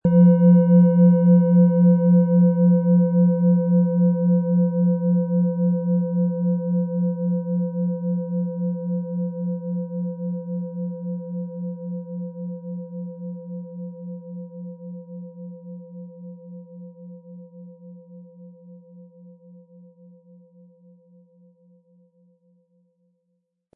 Perfekt zum sanften Anreiben und Anspielen, erzeugt sie eine fließende Schwingung, die Körper und Geist in Einklang bringt.
Ihre Schwingungen erzeugen eine ruhige, fließende Energie, die den Körper entspannt und die Gedanken klärt.
Spielen Sie die Schale mit dem kostenfrei beigelegten Klöppel sanft an und sie wird wohltuend erklingen.
PlanetentonWasser